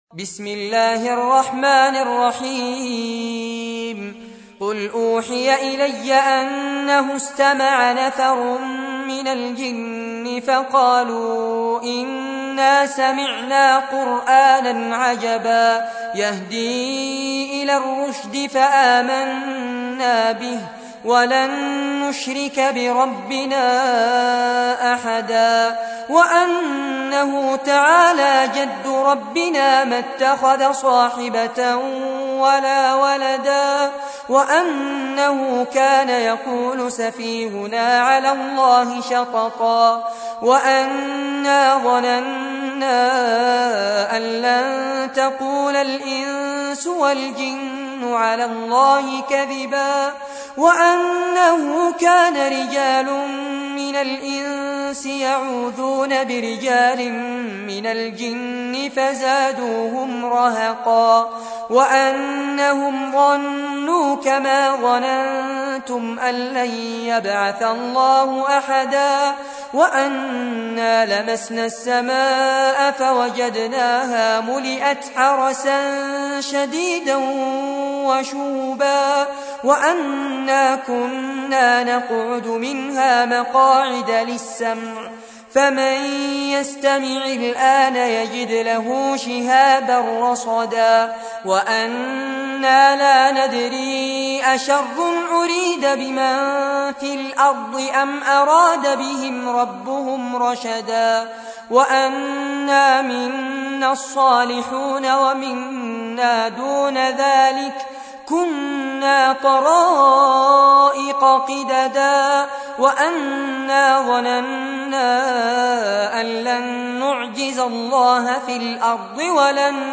تحميل : 72. سورة الجن / القارئ فارس عباد / القرآن الكريم / موقع يا حسين